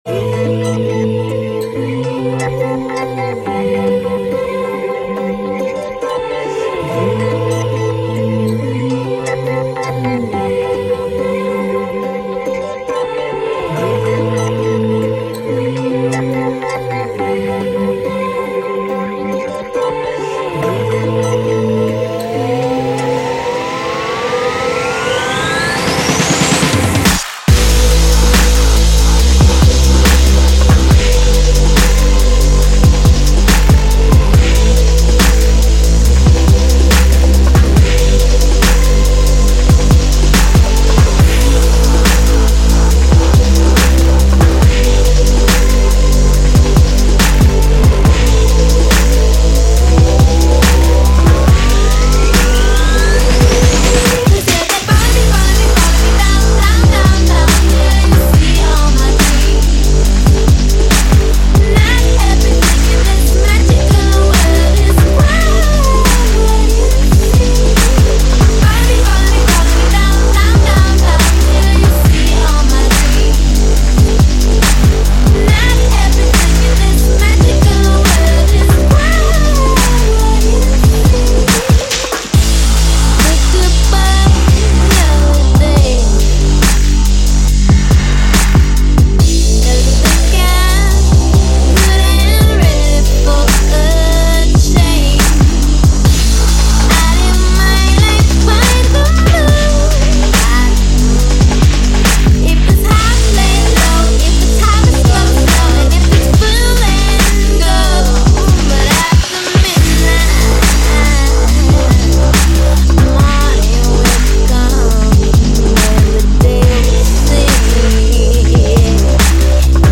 низкие чистоты